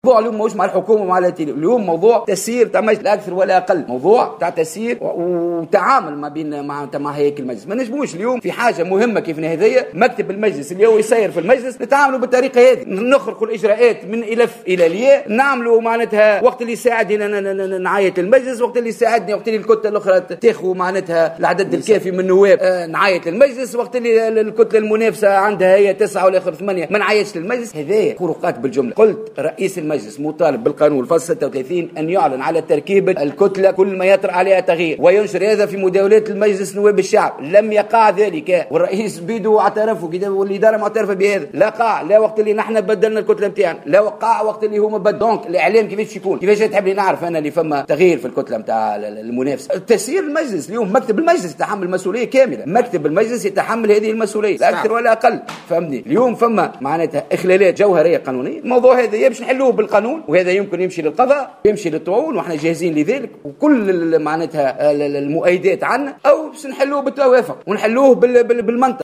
قال النائب عن كتلة أفاق تونس كريم هلالي خلال ندوة صحفية بمجلس نواب الشعب اليوم الثلاثاء إنّ الكتلة ستطعن في تركيبة مكتب المجلس الجديدة.